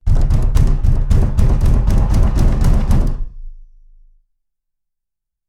doorpound.wav